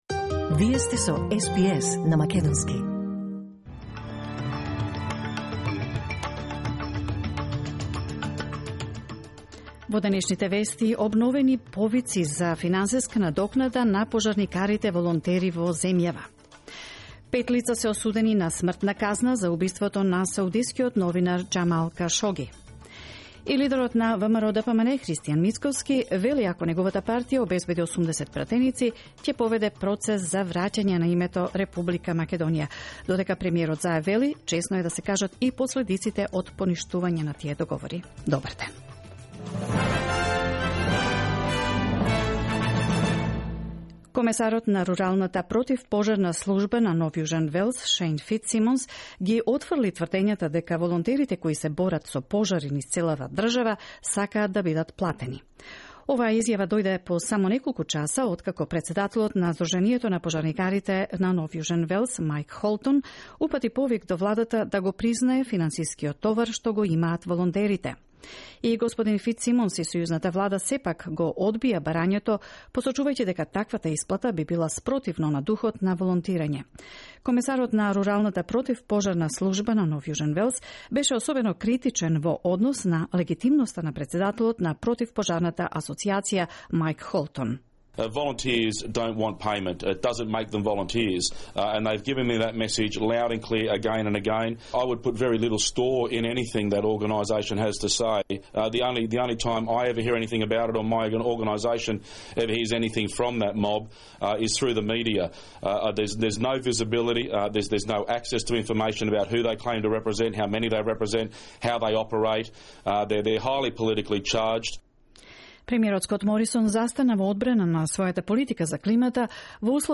SBS News in Macedonian, 24 December 2019